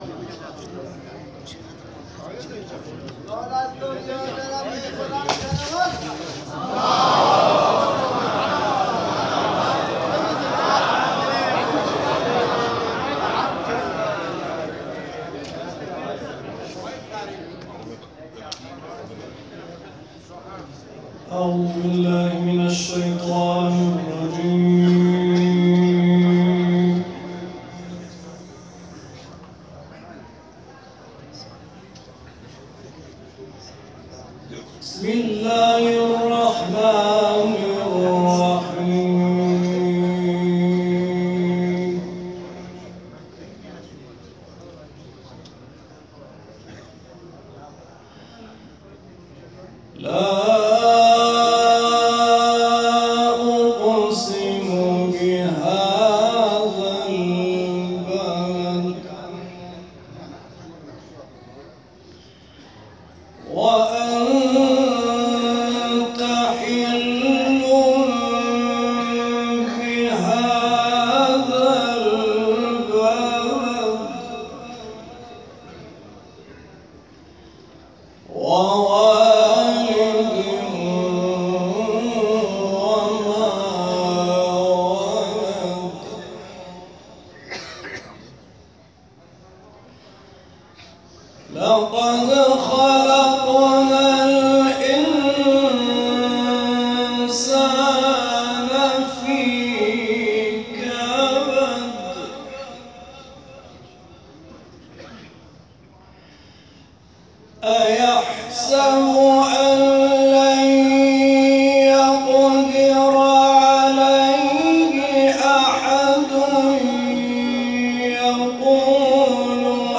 8 سوره بلد   /  مکه هتل الصقریه